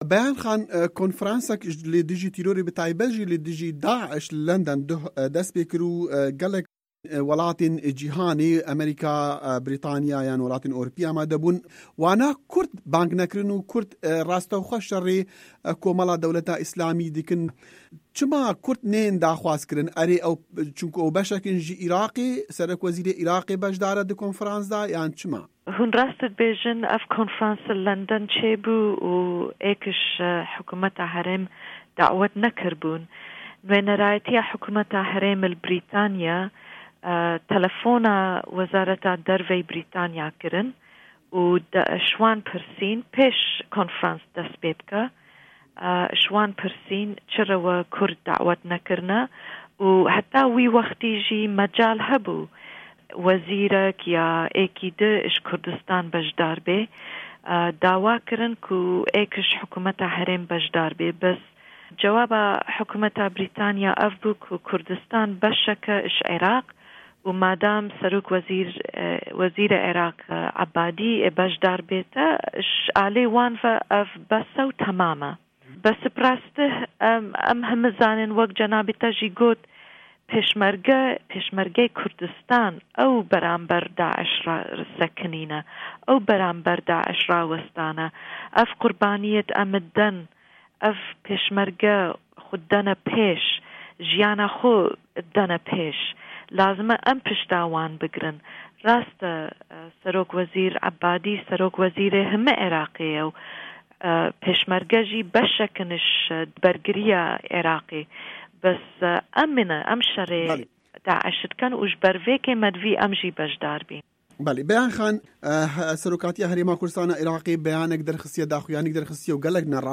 Di hevpeyvîna Dengê Amerîka de Nûnerê nû ya Hikûmeta Herêma Kurdistanê li Washingtonê Xanim Bayan Samî Abdulraman li ser çend babetên girîng nirxandinan dike.